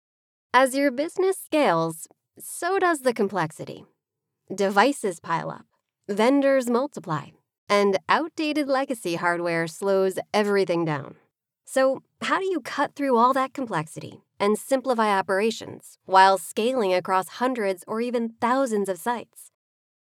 Female
American English (Native)
Approachable, Assured, Authoritative, Bright, Bubbly, Character, Cheeky, Confident, Conversational, Cool, Corporate, Deep, Energetic, Engaging, Friendly, Funny, Gravitas, Natural, Posh, Reassuring, Sarcastic, Smooth, Soft, Upbeat, Versatile, Wacky, Warm, Witty, Young
My voice sits in the millennial / Gen Z range – from early 20s to 40s, with a General American accent.
Commercial Demo_4.mp3
Microphone: Sennheiser MKH416